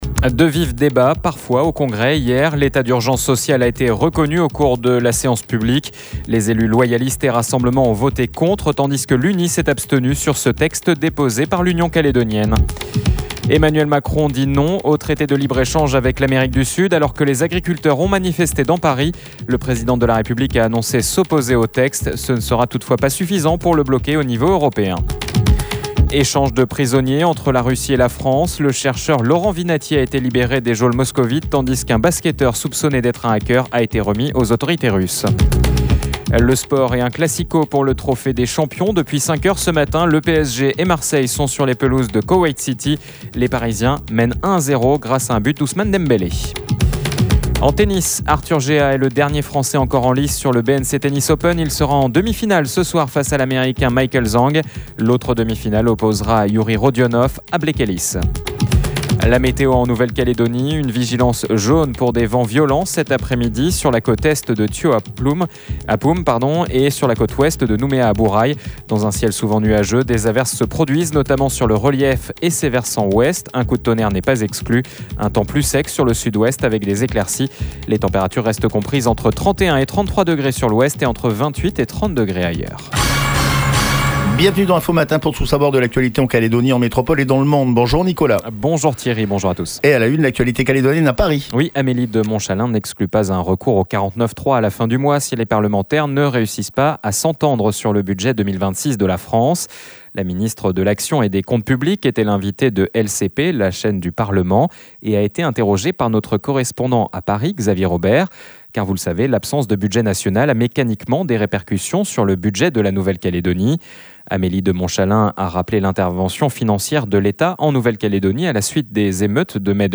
Quelles avancées pour la reconstruction du territoire ? La directrice de la mission interministérielle de reconstruction, Claire Durrieu, était notre invitée ce matin à 7h30. Trois objectifs clairs avaient été fixés par les membres de la mission fin 2025, trois objectifs en partie atteints selon Claire Durrieu.